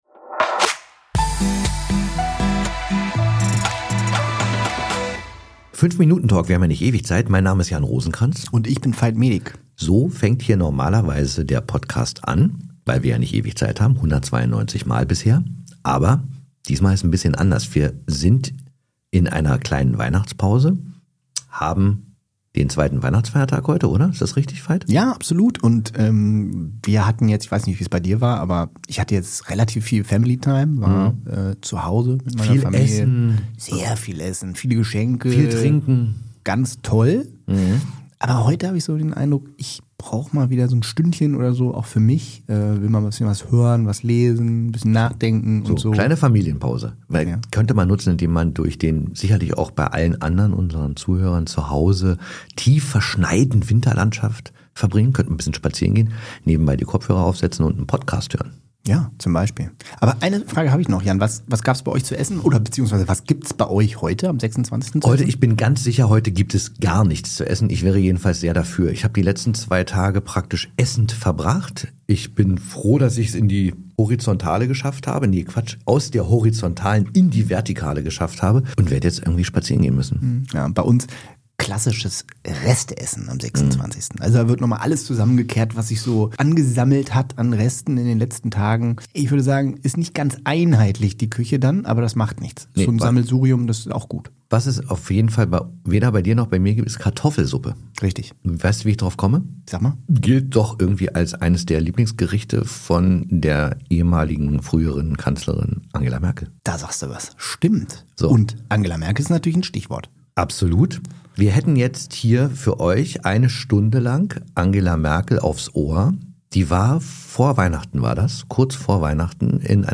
Hören Sie das gesamte Interview als Sonderfolge des ausnahmsweise deutlich längeren 5-Minuten-Talks- Denn: Zwischen den Jahren haben wir ja mal ein bisschen mehr Zeit.